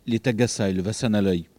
Mémoires et Patrimoines vivants - RaddO est une base de données d'archives iconographiques et sonores.
Localisation Soullans
Catégorie Locution